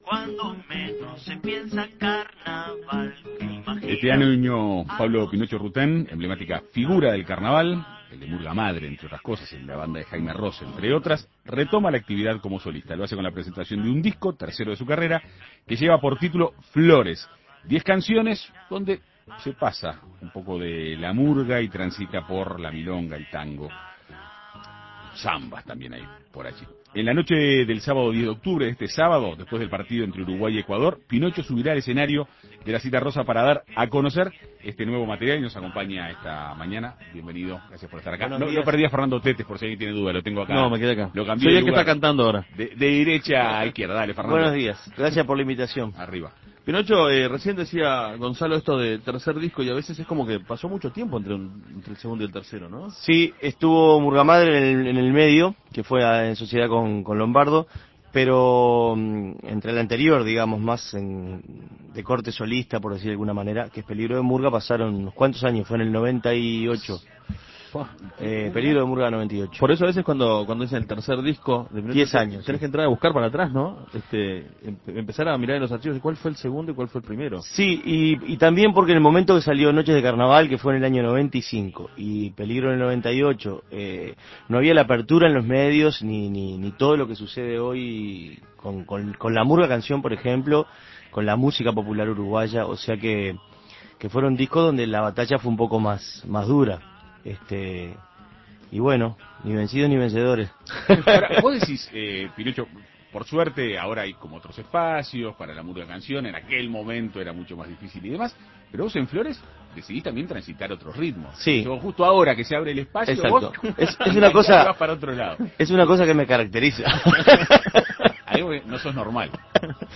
En Perspectiva Segunda Mañana dialogó con el artista.